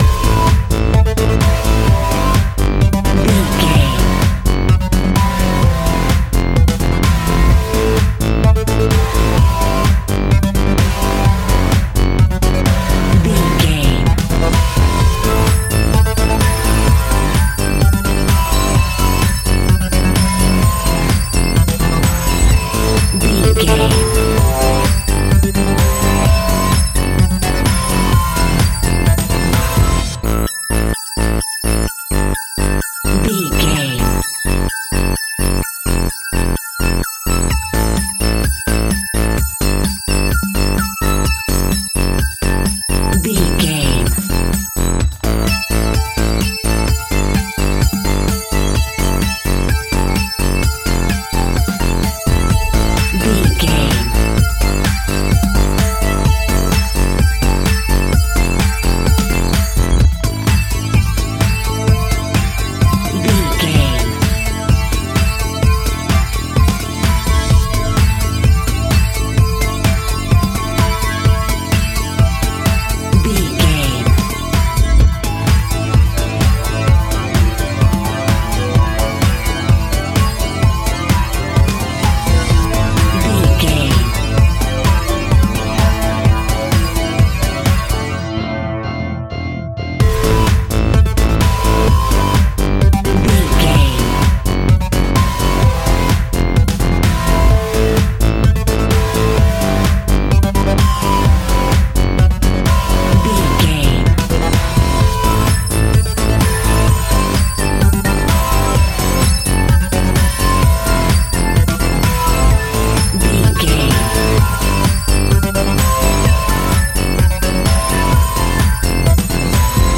Epic / Action
Fast paced
Aeolian/Minor
dark
futuristic
groovy
energetic
synthesiser
drum machine
electric piano
electro house
synth bass
synth lead